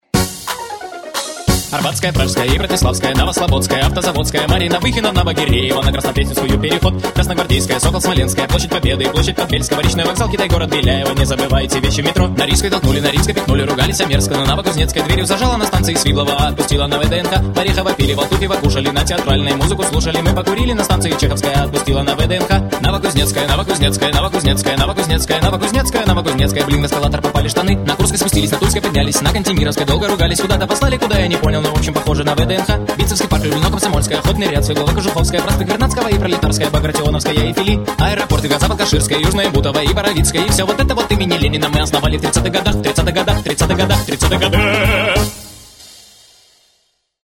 Музон весёлый.